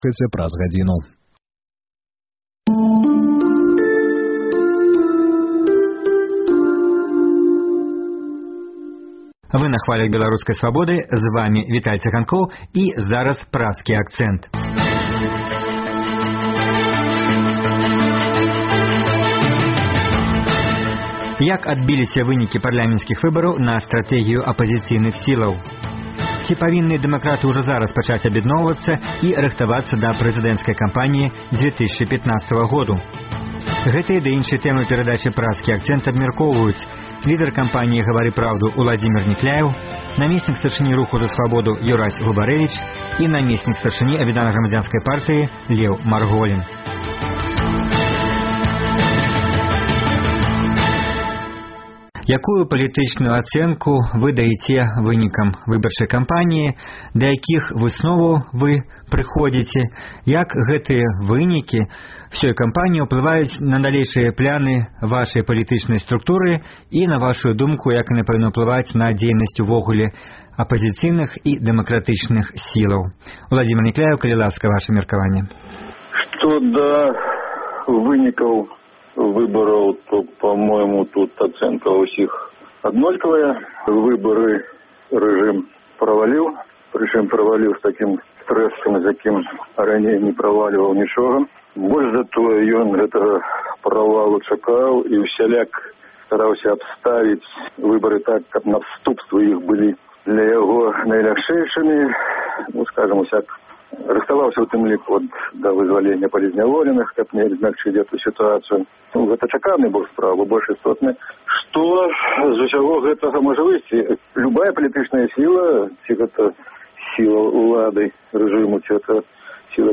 Гэтыя ды іншыя тэмы ў перадачы "Праскі акцэнт" абмяркоўваюць лідэр кампаніі Гавары праўду Уладзімер Някляеў